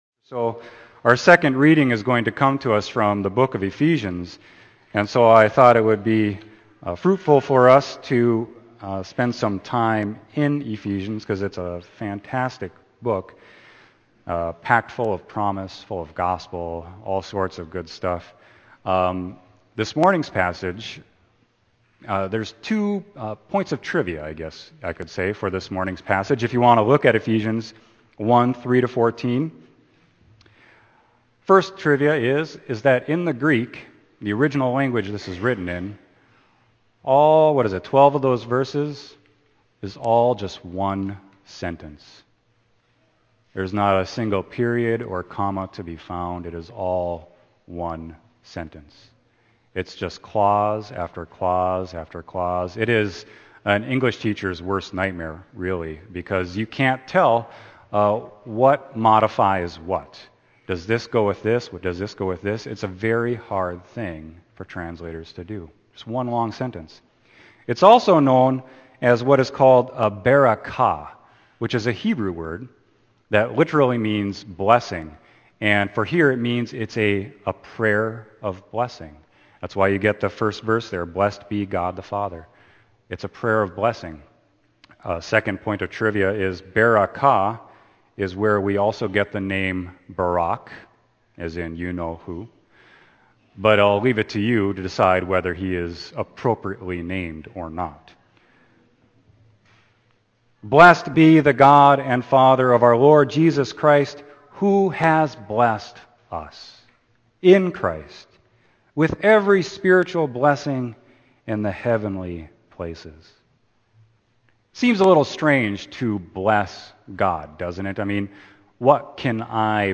Sermon: Ephesians 1.3-14